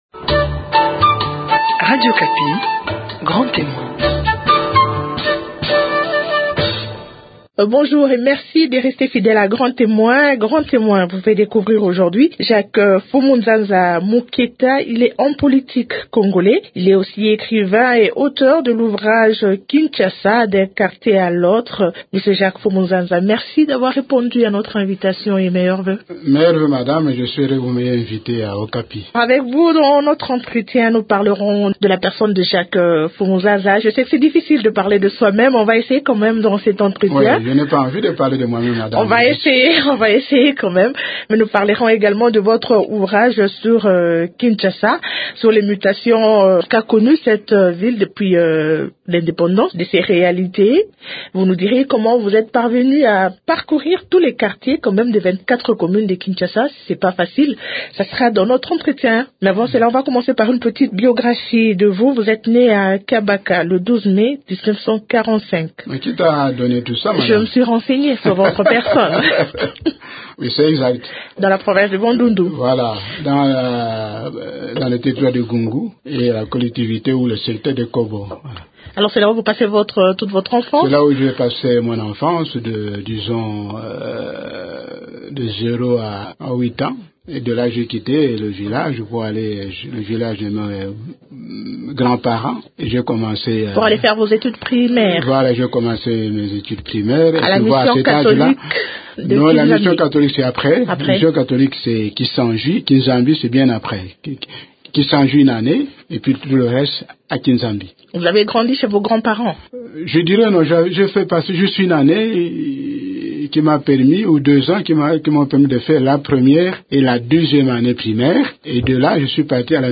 Dans cet entretien